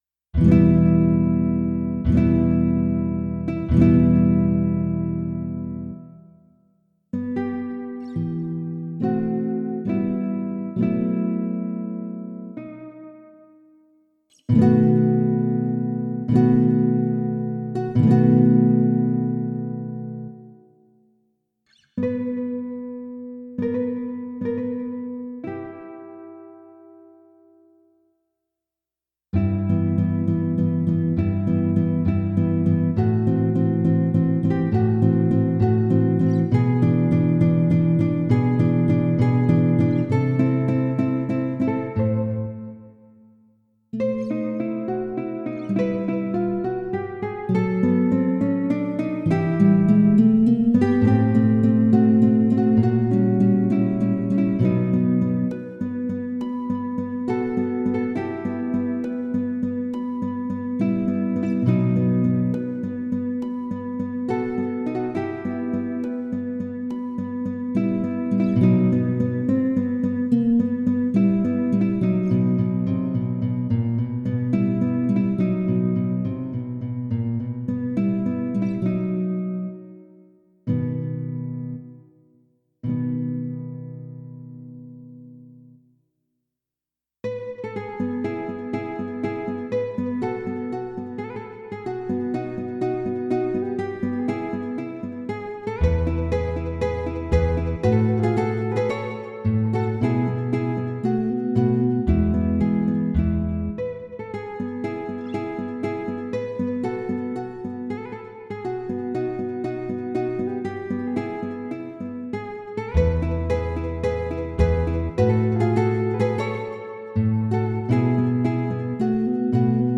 ＭＩＤＩ クラシックギター 魔笛の主題による変奏曲より序奏と主題 (Intro & Theme)
この変奏曲の中から序奏（Introduction）、主題 Theme)、変奏その１(Variation I)、変奏その２(Variation II)をＭＩＤＩ演奏しています。